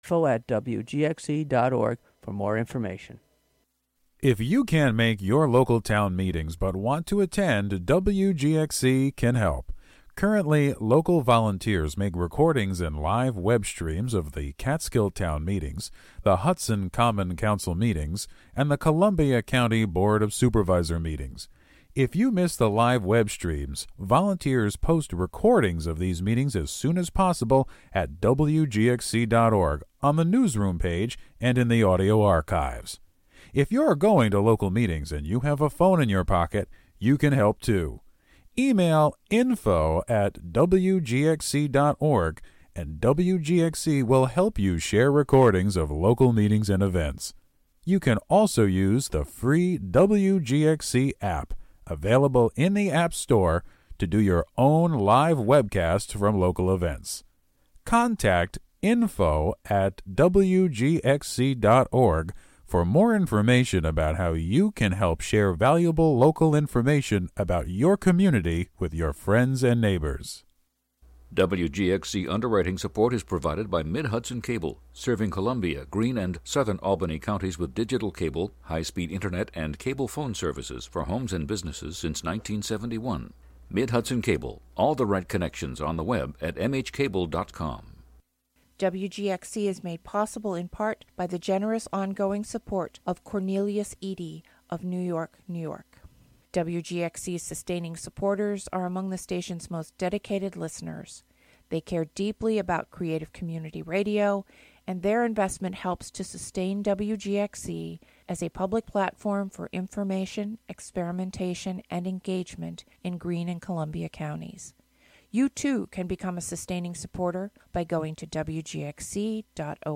Show includes local WGXC news at beginning, and midway through. Get yr weekly dose of music appreciation, wordsmithing, and community journalism filtered through the minds and voices of the Youth Clubhouses of Columbia-Greene, broadcasting out of the Catskill Clubhouse, live on Fridays as part of All Together Now! and rebroadcast Saturday at 4 a.m. and Sunday at 10 a.m. Play In New Tab (audio/mpeg) Download (audio/mpeg)